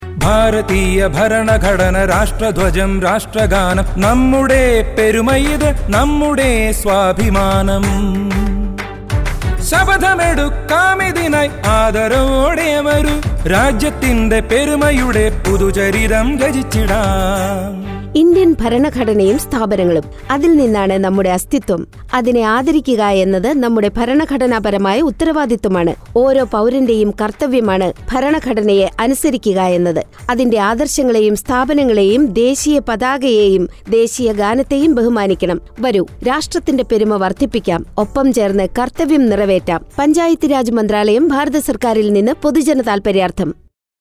124 Fundamental Duty 1st Fundamental Duty Abide by the Constitution and respect National Flag and National Anthem Radio Jingle Malayamlam